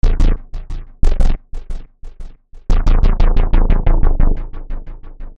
Tag: 90 bpm Hip Hop Loops Synth Loops 918.92 KB wav Key : Unknown